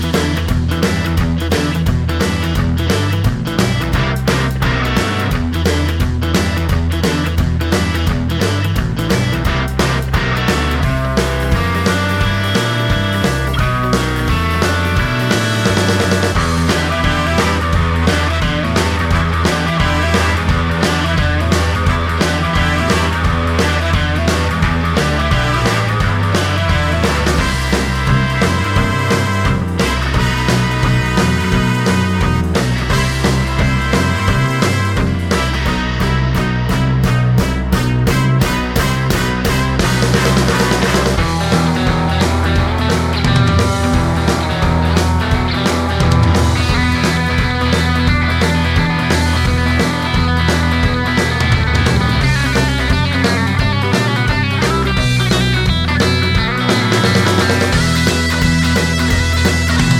no Backing Vocals Jazz / Swing 2:46 Buy £1.50